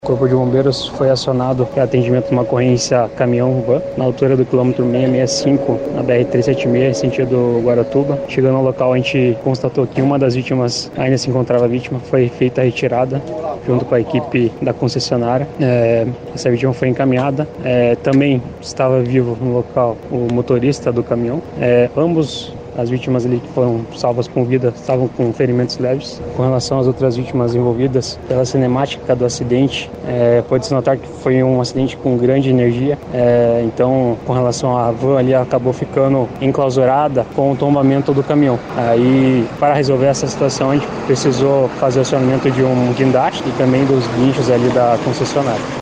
SONORA-ACIDENTE-REMO-01-BO.mp3